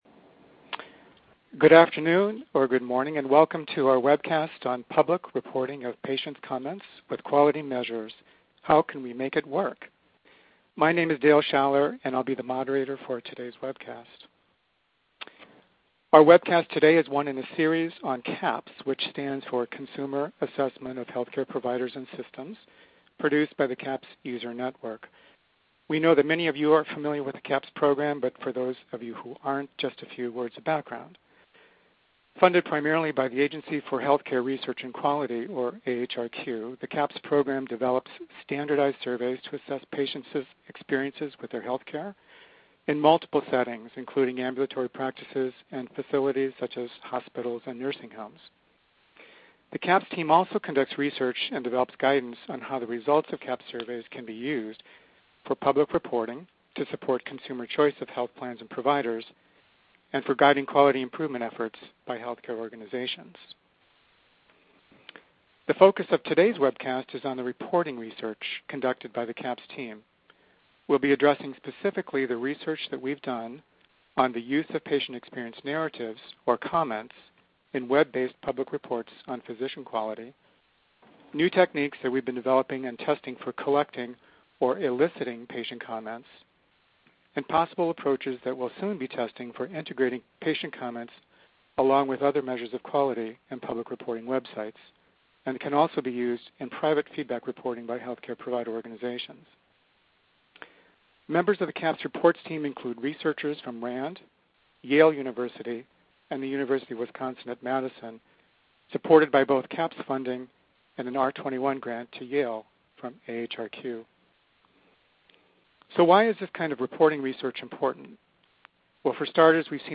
In this Webcast, researchers discussed an ongoing AHRQ study of methods for eliciting representative patient comments and reporting those comments in a coherent manner with standardized measures of physician quality, including CAHPS measures of patient ex